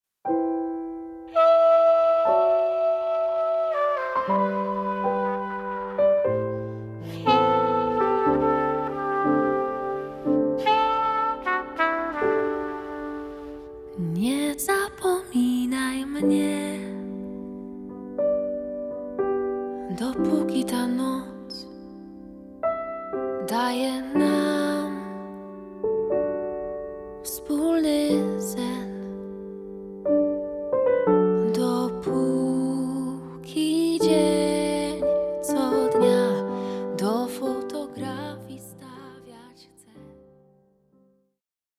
kontrabas
fortepian
trąbka
saksofon sopranowy i tenorowy
gitara
perkusja, instr. perkusyjne